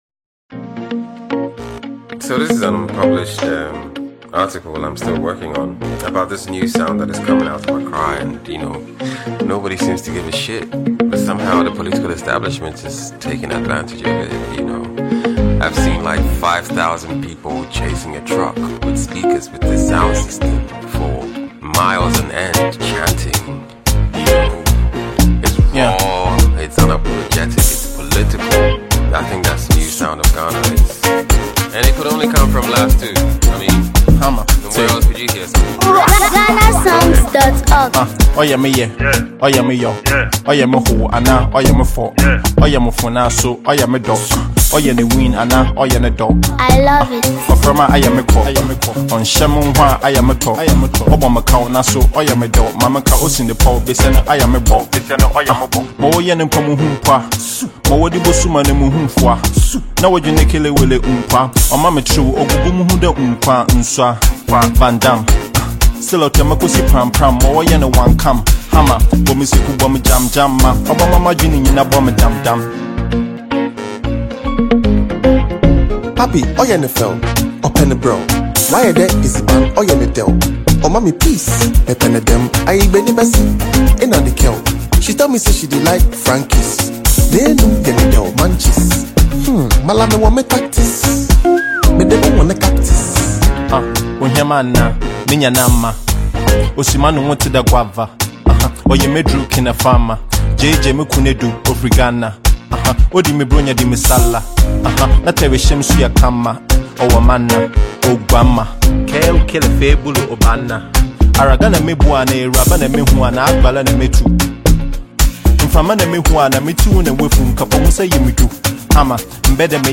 vibrant and catchy song